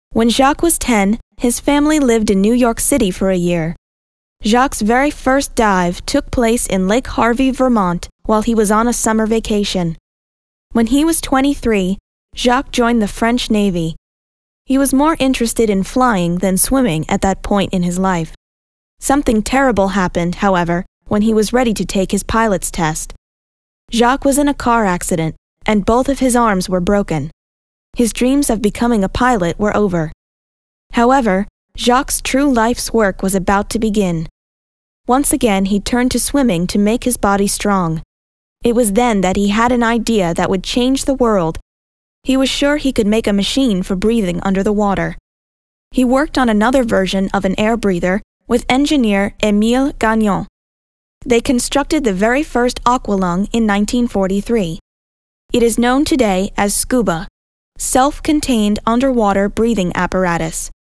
Audiobooks and E-learning